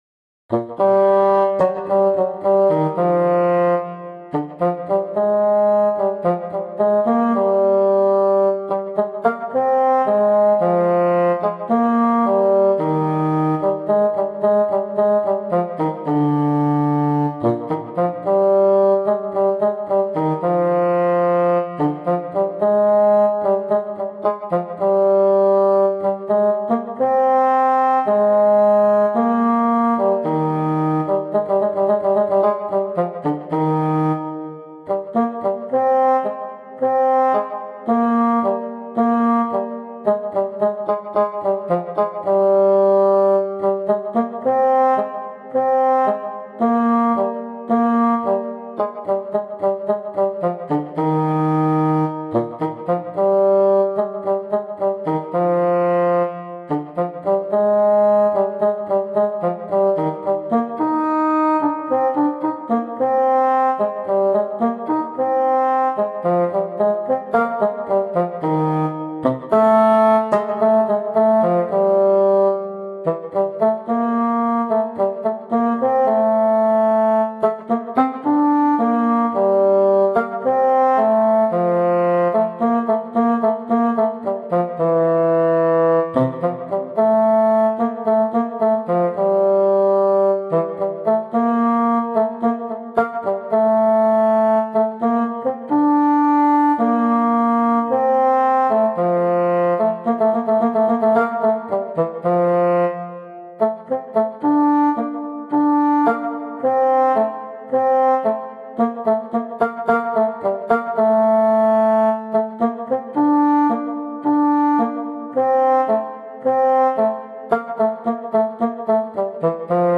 Соло фагота в музыке